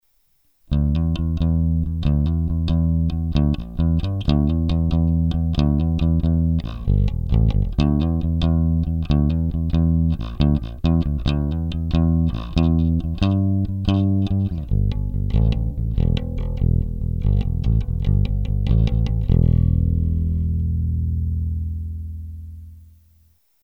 Треки записывал напрямую в линейный вход звуковой карточки без какой либо текущей и последующей обработки звука.
Bass track 2
Треки 1, 2 и 3 записаны при положении всех регулировках (High, Bass) на максимуме; 1-й трек - при включенном "Bright" (т.е. при самом верхнем положении тумблера SW 1); 3-й трек - при отсечке одной катушки хамбакера (т.е. при тумблере SW 1 в среднем положении)
bass_track2.mp3